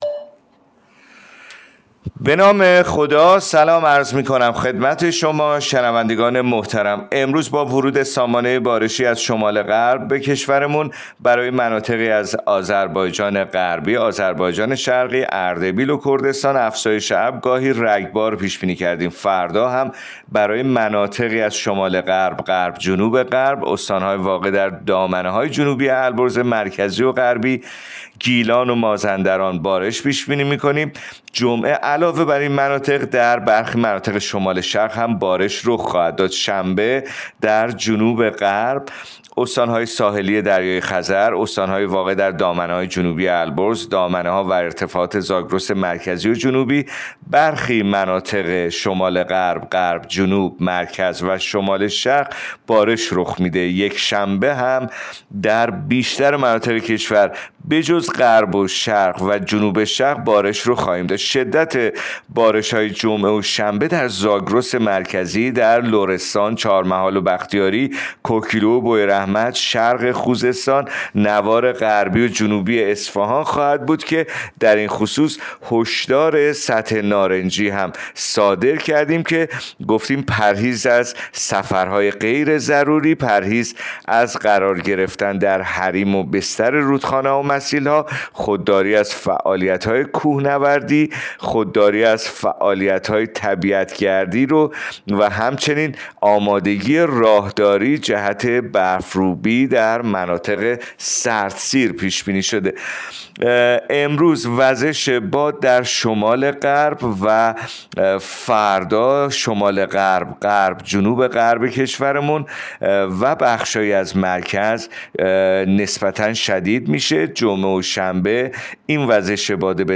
گزارش رادیو اینترنتی پایگاه‌ خبری از آخرین وضعیت آب‌وهوای ۲۹ اسفند؛